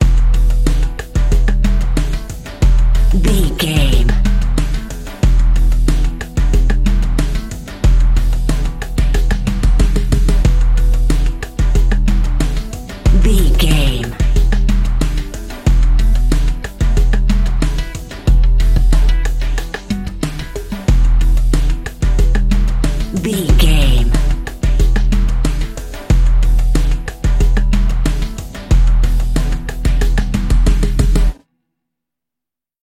Aeolian/Minor
synthesiser
drum machine
hip hop
Funk
neo soul
acid jazz
confident
energetic
bouncy
funky